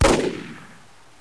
RIFLE_4.WAV